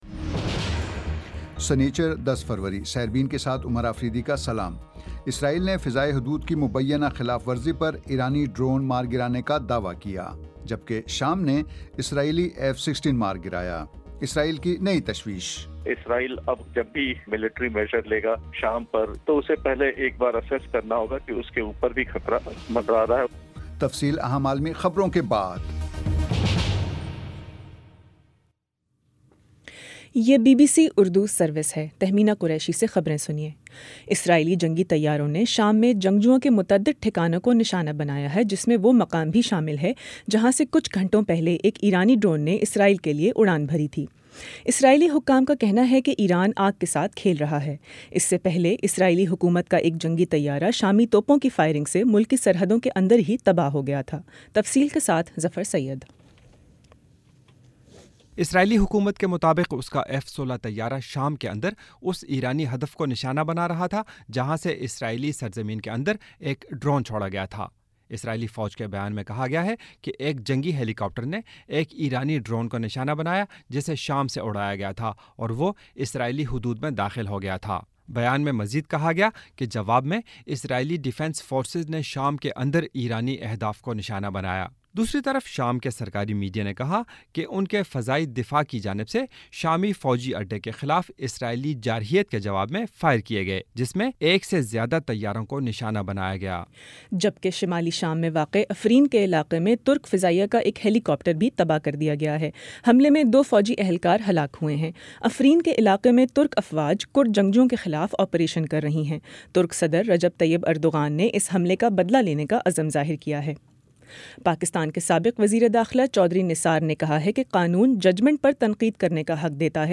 ہفتہ 10 فروری کا سیربین ریڈیو پروگرام